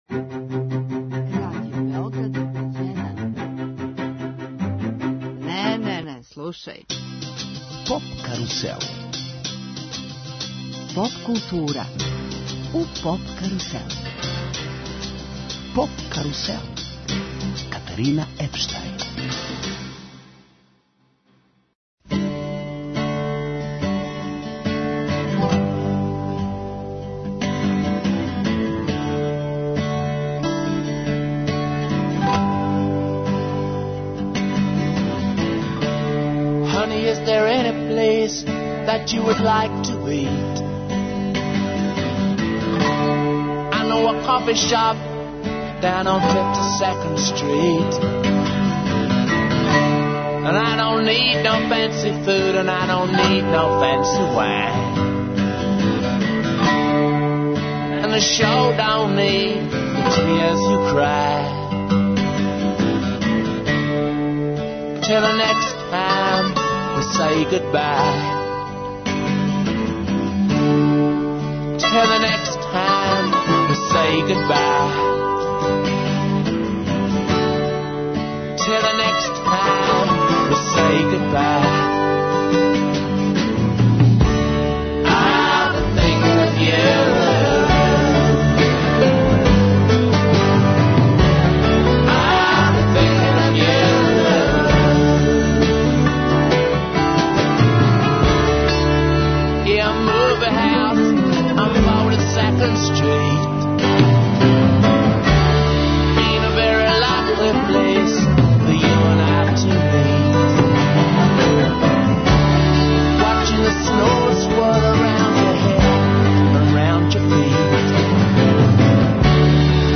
Гости емисије су чланови једног од најпознатијих регионалних састава Ватра, који ће одсвирати, акустично, неке од својих највећих хитова.